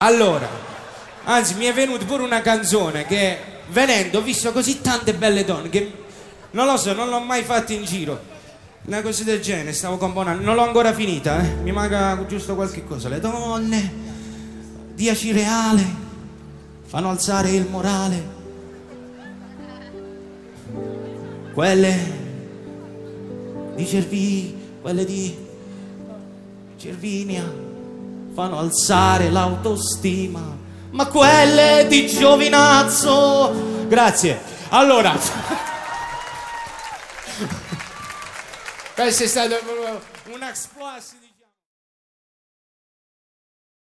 Una piccola perla tratta da un live… :joy: